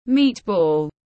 Thịt viên tiếng anh gọi là meat ball, phiên âm tiếng anh đọc là /miːt bɔːl/